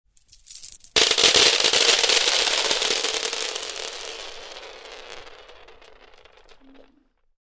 На этой странице собраны разнообразные звуки бус: от легкого перекатывания бусин до их мелодичного звона.
Звук скатывающихся по полу бусинок